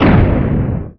thud.wav